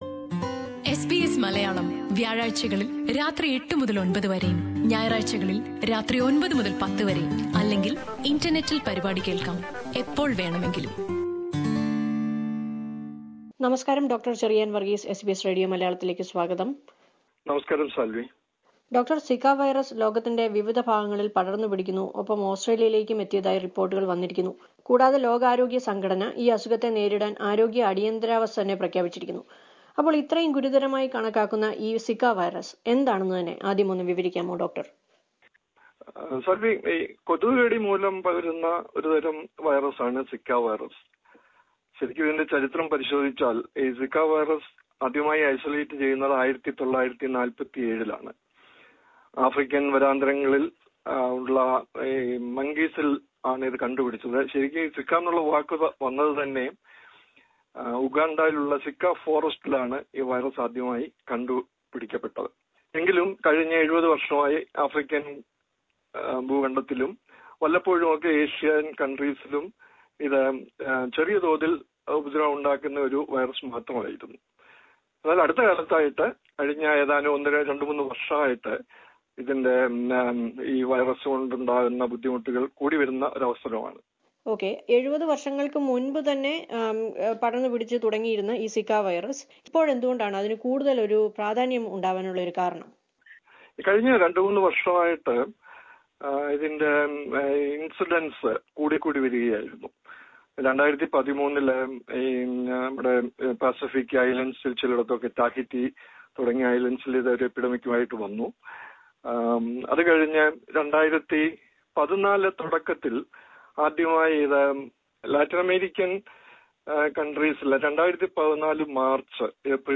talks to SBS Malayalam Radio about the disease.